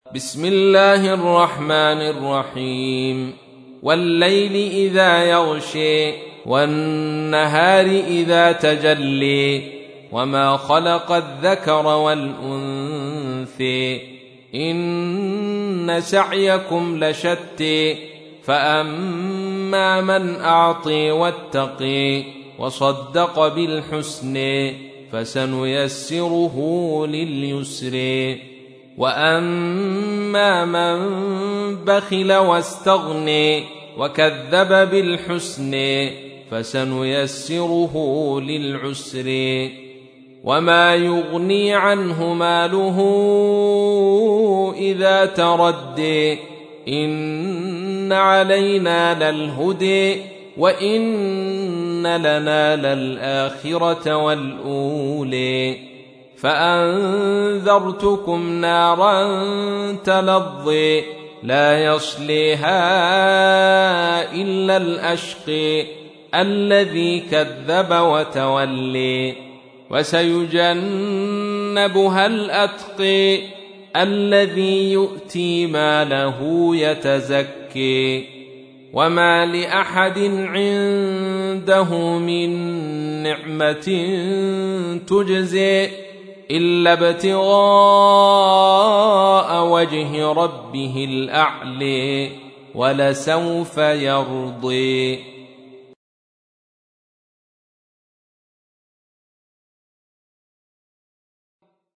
تحميل : 92. سورة الليل / القارئ عبد الرشيد صوفي / القرآن الكريم / موقع يا حسين